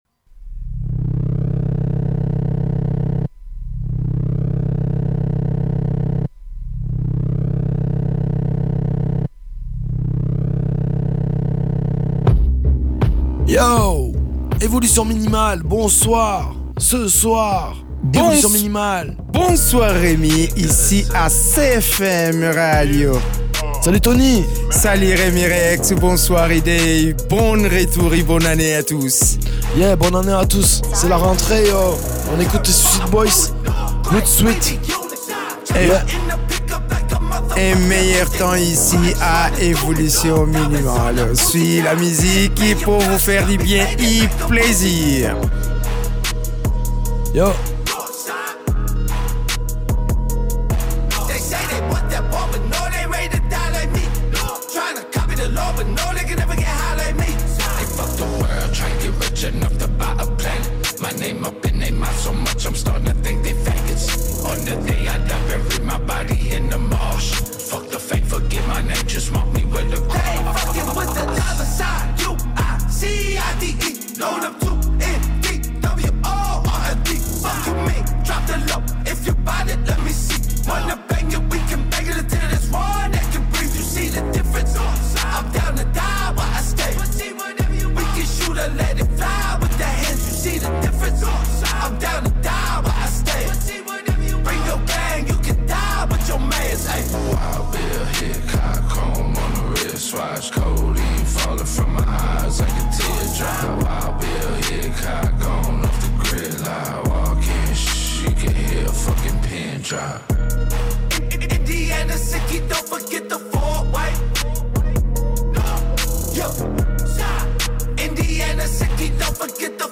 Une émission bien barrée avec presque que des nouveautés. L’actualité musicale à cette saison a été très bien fournie.
Une playlist déjantée!